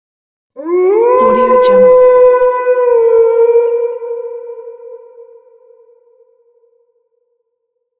Halloween Wolf Howling Bouton sonore
The Halloween Wolf Howling sound button is a popular audio clip perfect for your soundboard, content creation, and entertainment.